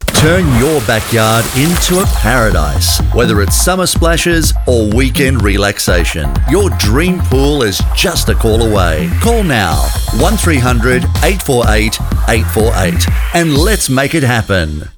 Male
My accent is Australian English.
Radio Commercials
Words that describe my voice are Australian, Narrator, Voice over.